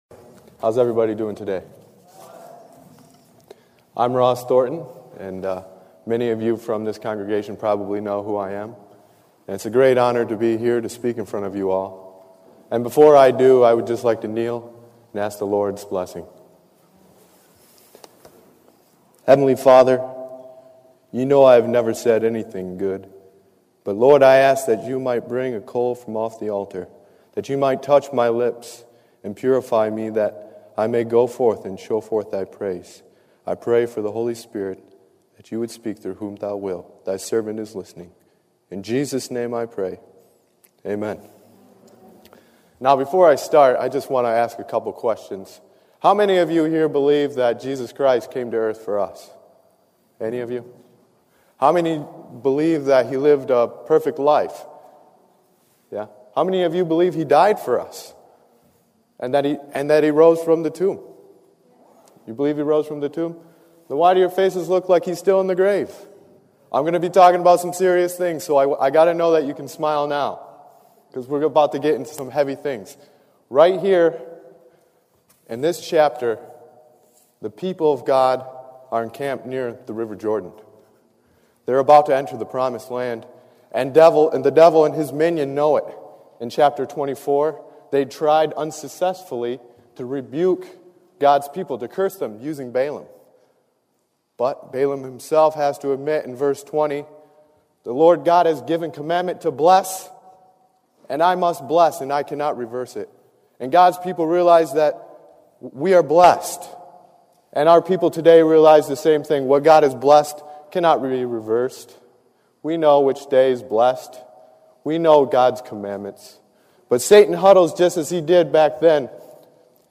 Tags: Sermon Preaching Jesus Bible God